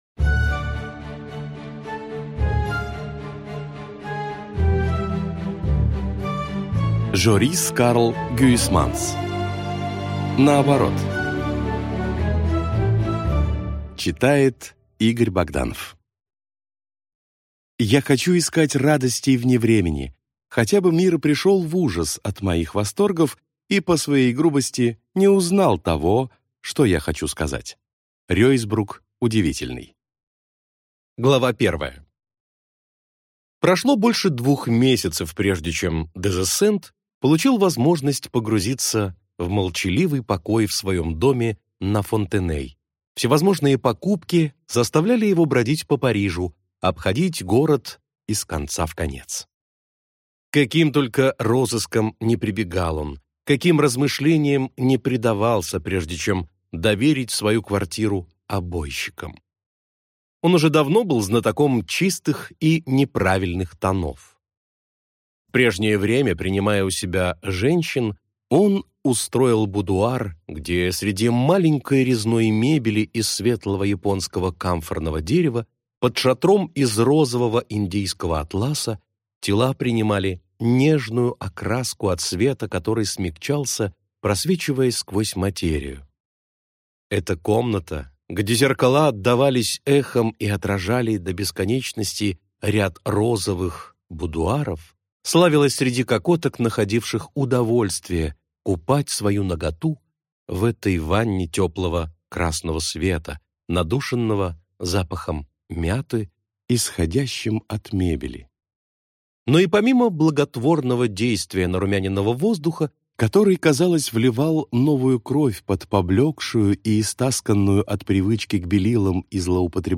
Аудиокнига Наоборот | Библиотека аудиокниг
Прослушать и бесплатно скачать фрагмент аудиокниги